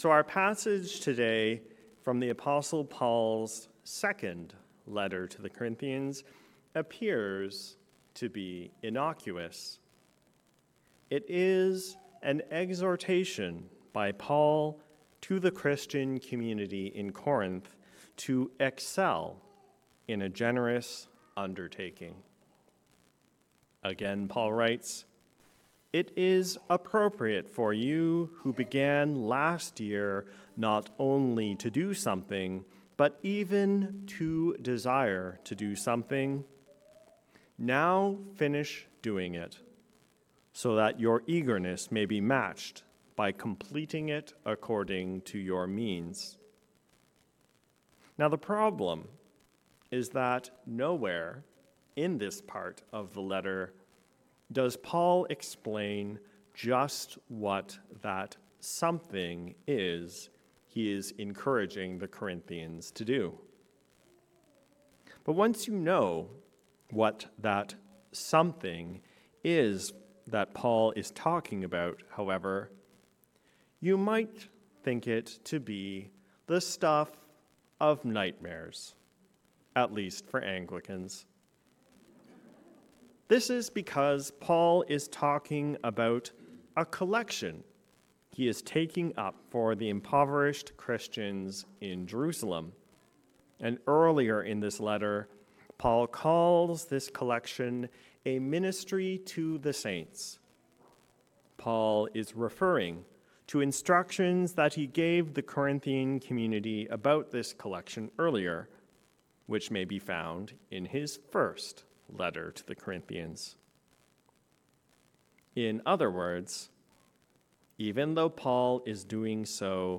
A Sermon for the Sixth Sunday After Pentecost